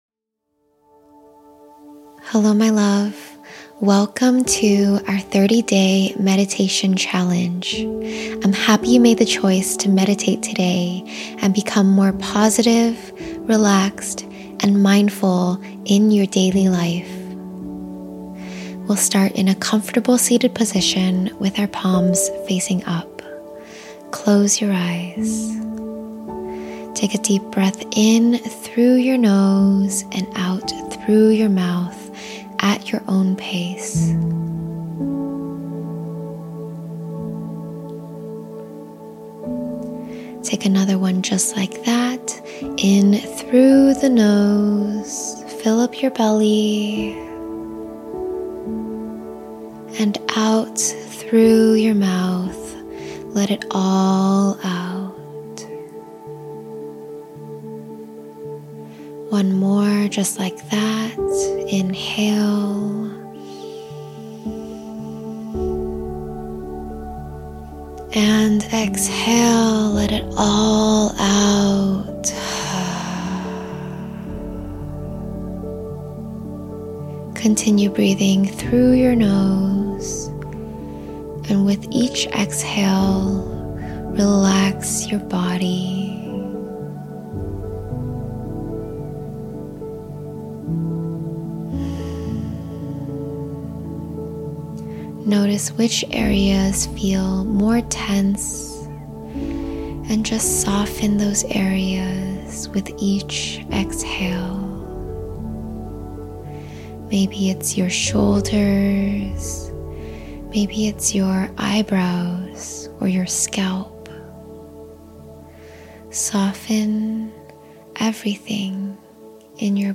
5 Minute Meditation for Relaxation & Positive Energy | 30 Day Meditation Challenge